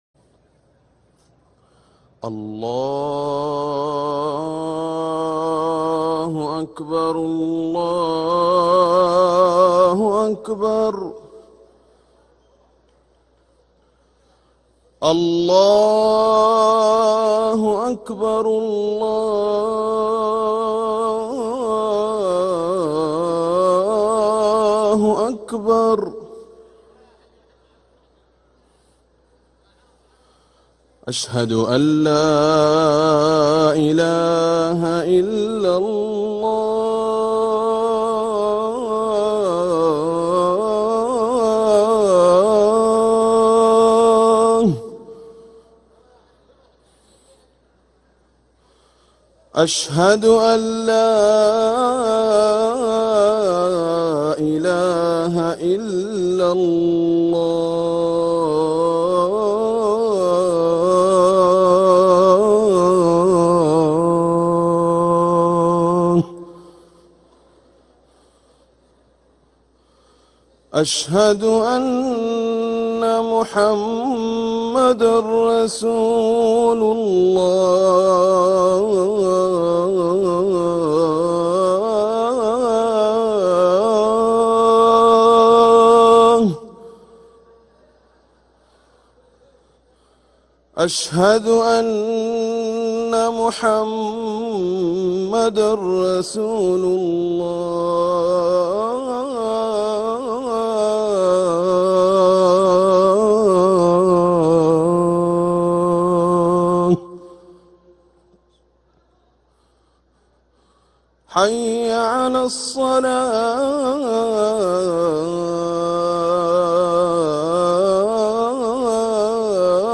أذان الجمعة الاول